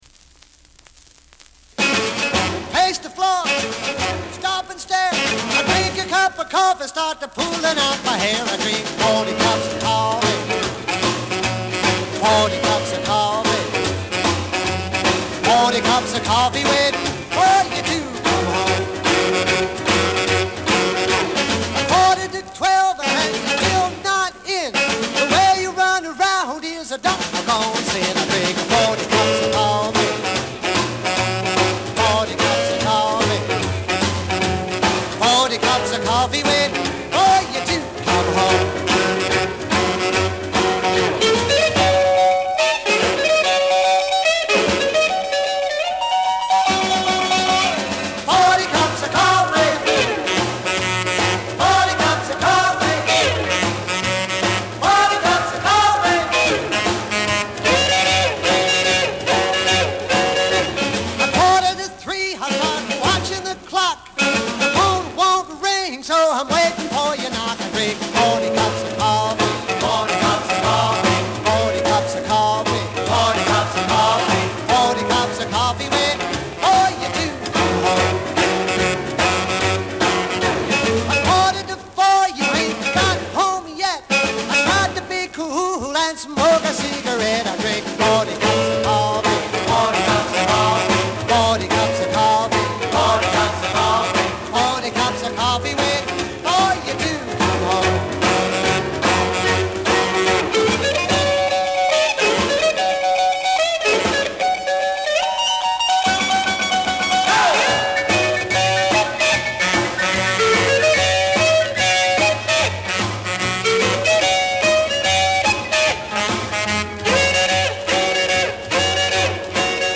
45 RPM vinyl record
were an American Rock and Roll band founded in 1952.